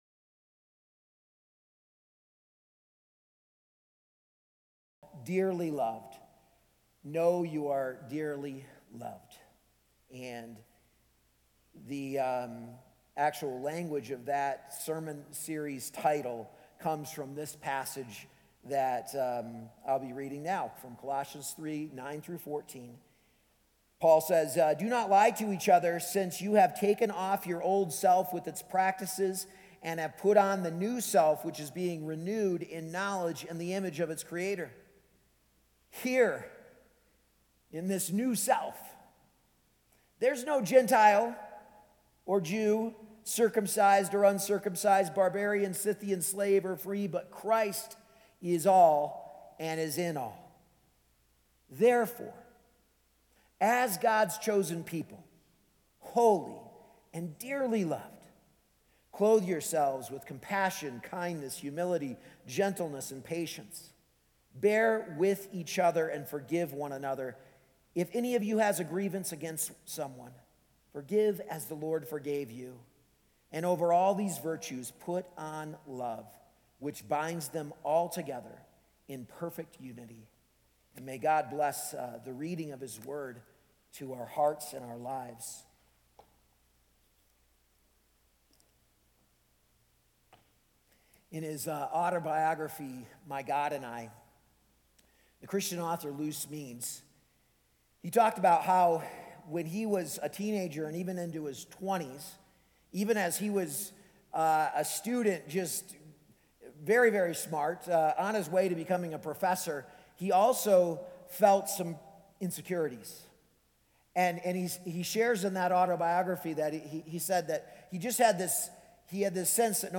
A message from the series "Dearly Loved."